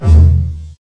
标签： mediawhore 扭曲 样品 混合 坏的 噪声 朋克 学校 DIY 字段 记录 低保真
声道立体声